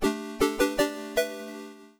Jingles
Success3.wav